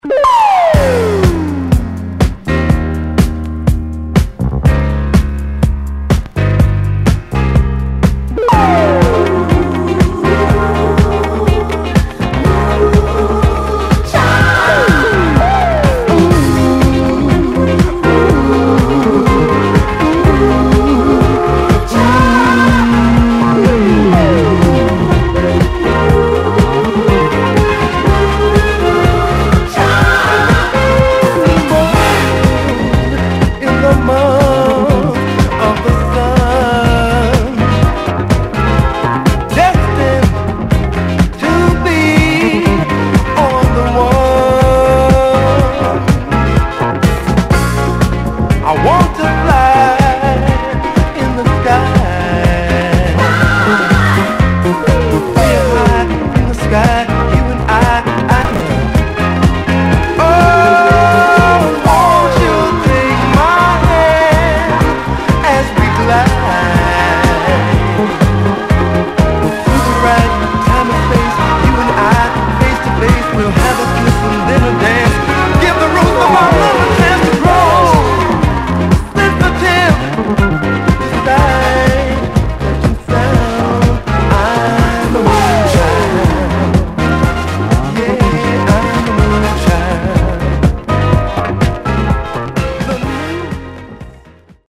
US 12'は$500~$700位で取引される人気Disco!!